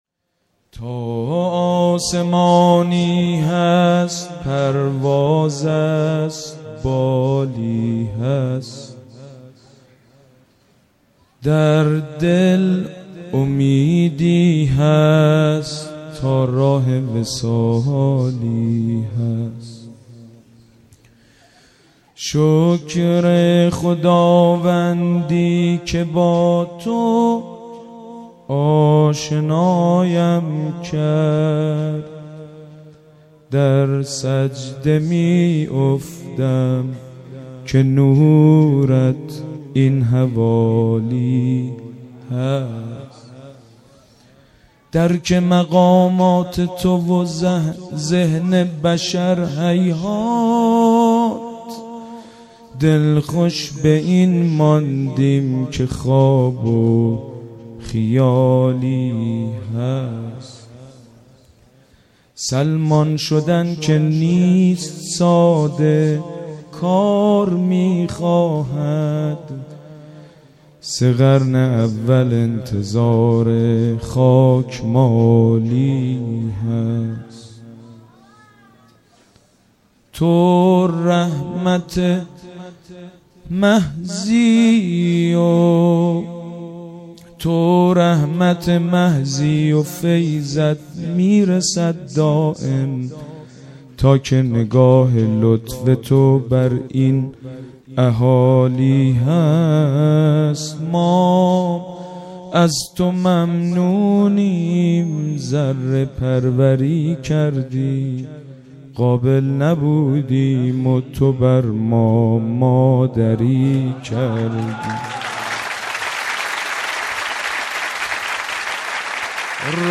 قالب : مدح